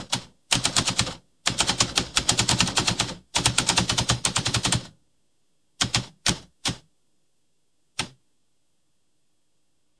Звук печатной машинки